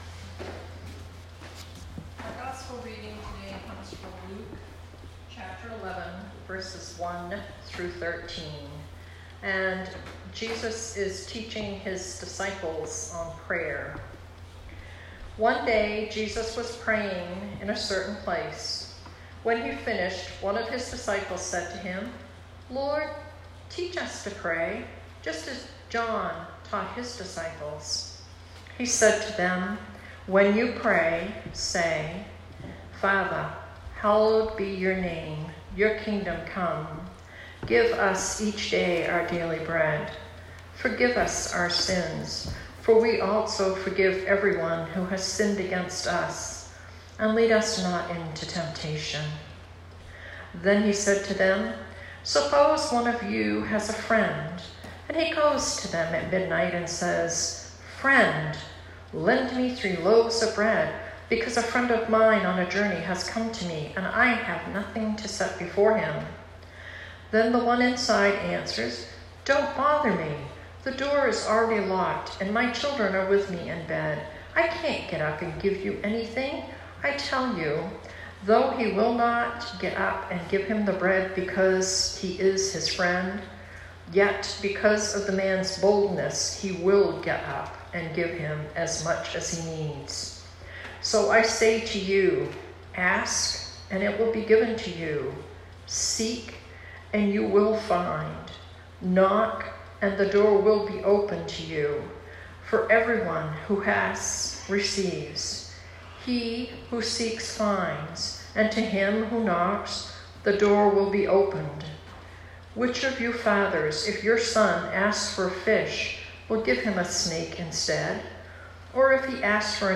Sermon 2019-07-28